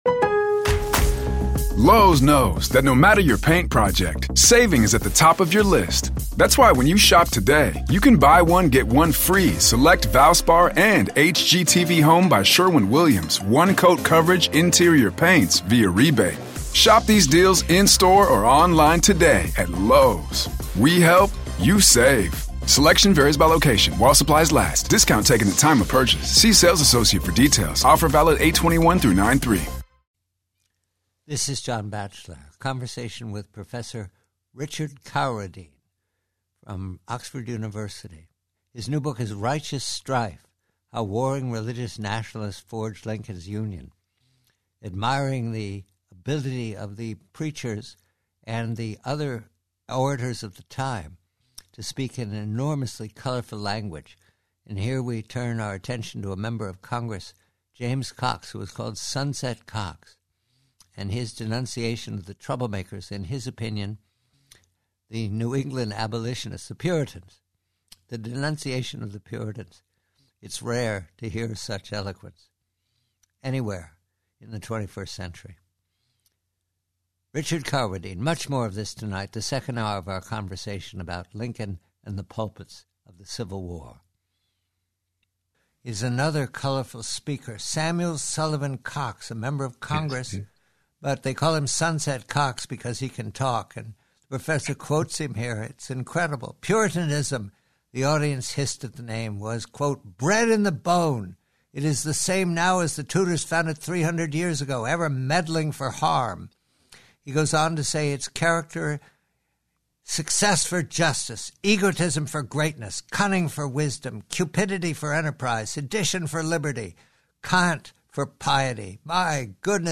PREVIEW: Conversation